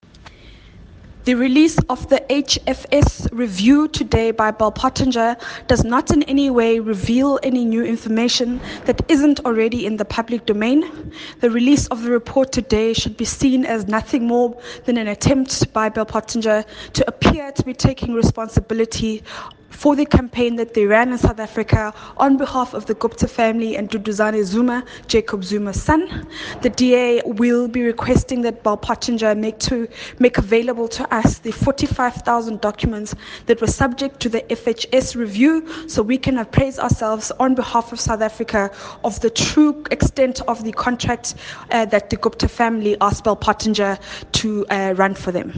soundbite by the DA Shadow Minister of Communications, Phumzile Van Damme MP.